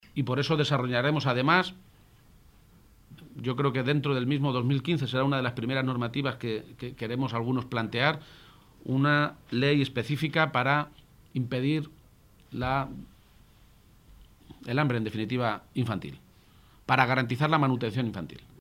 García-Page se pronunciaba de esta manera esta mañana, en Toledo, en declaraciones a los medios de comunicación durante la firma de un convenio con Cruz Roja.
Cortes de audio de la rueda de prensa